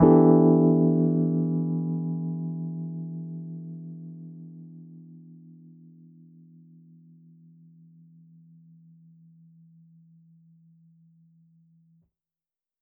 JK_ElPiano3_Chord-Em11.wav